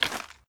Weapon_Foley 07.wav